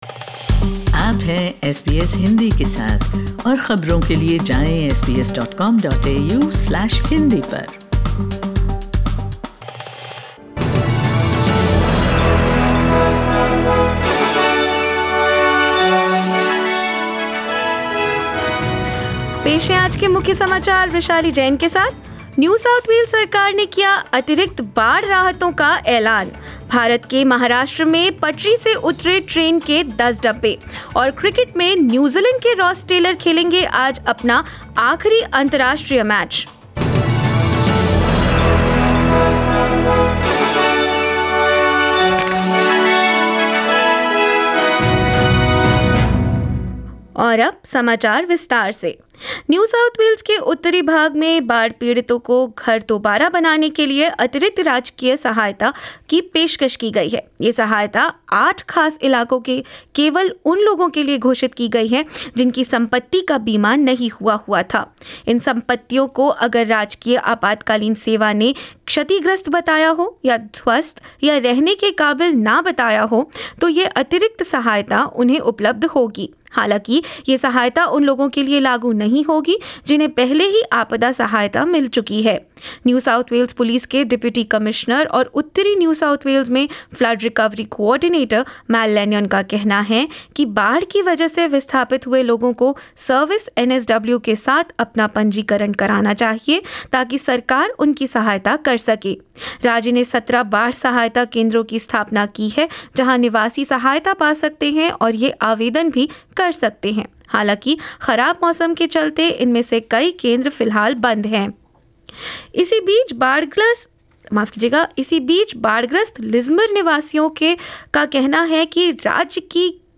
In this latest Hindi bulletin: NSW government announces additional grants for uninsured homeowners in flood affected areas; 10 carriages of a train derail in India, no casualties reported; Ace New Zealand cricketer Ross Taylor plays his last international match against Netherlands and more news.